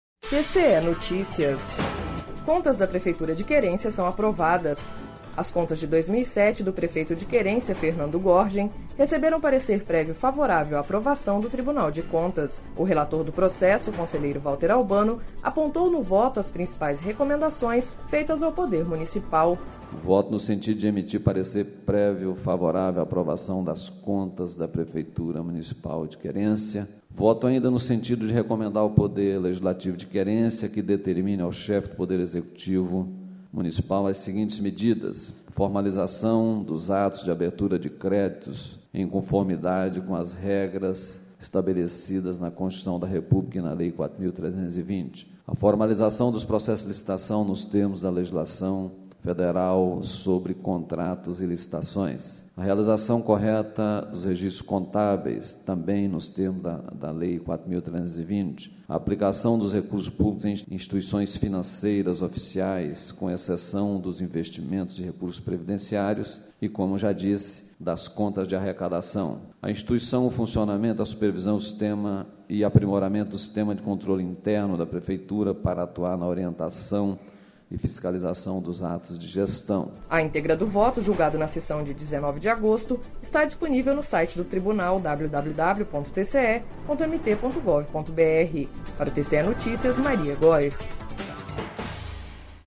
Sonora: Valter Albano - conselheiro TCE-MT